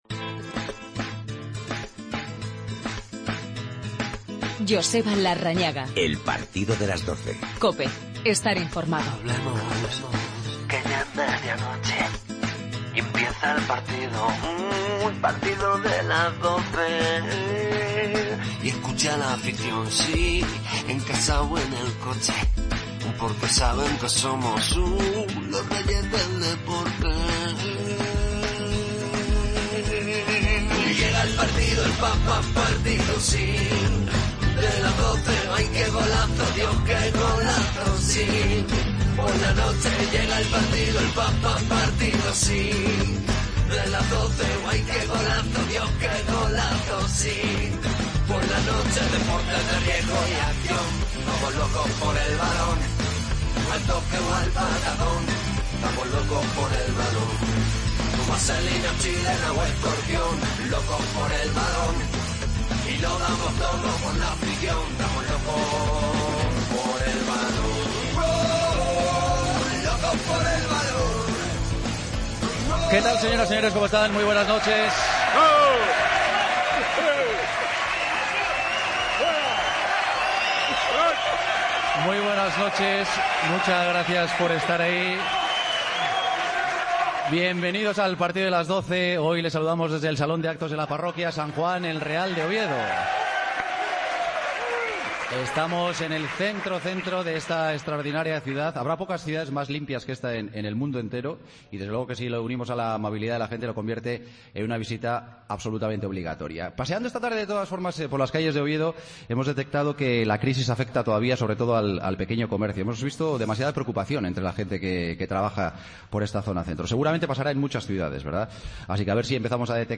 AUDIO: Programa especial desde Oviedo.